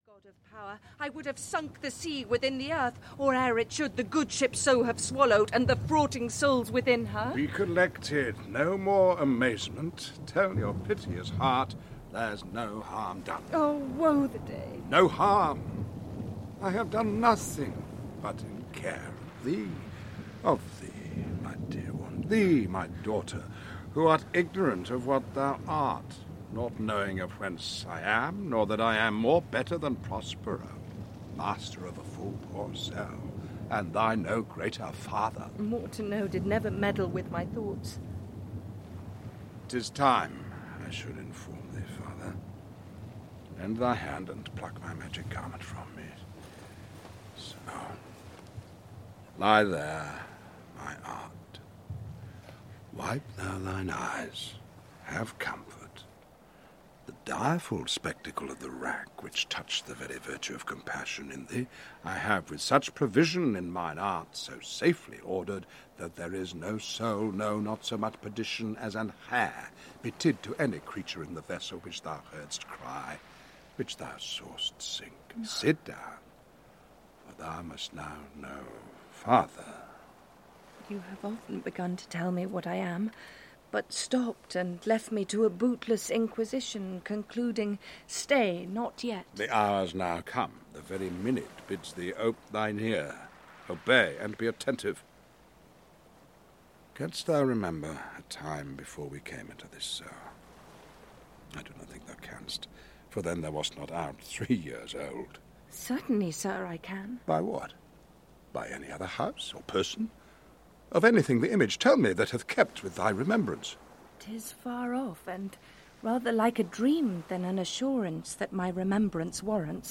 Audiobook The Tempest by William Shakespeare.
Sir Ian McKellen, fresh from his performance as Gandalf in Lord of the Rings is Prospero, and heads a strong cast in Shakespeare’s last great play. The wronged duke raises a tempest to shipwreck his old opponents on his island so that he can ensure justice is done.